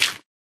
Sound / Minecraft / dig / gravel3